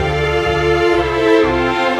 Rock-Pop 11 Strings 03.wav